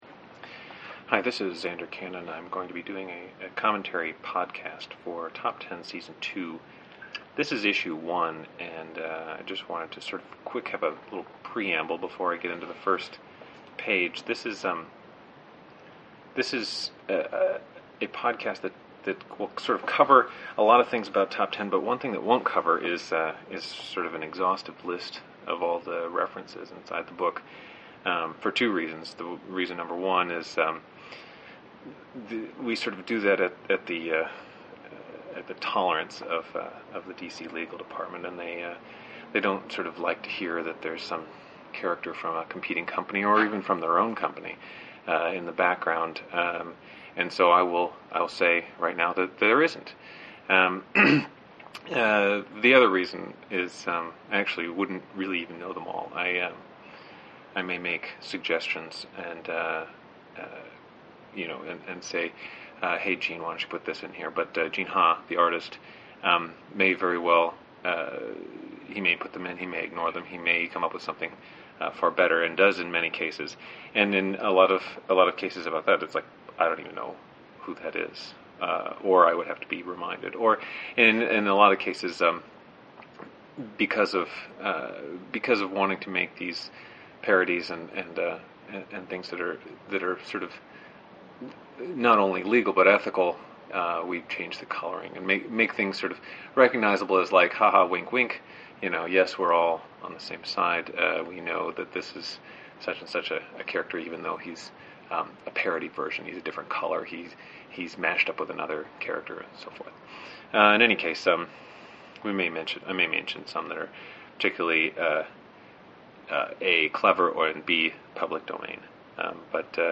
The people who want to listen to it are self-selecting; they presumably all have the comic already, so all that is needed is to sit in front of a microphone and chitchat for a while.